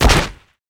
徒手攻击击中-YS070510.wav
通用动作/01人物/03武术动作类/徒手攻击击中-YS070510.wav
• 声道 單聲道 (1ch)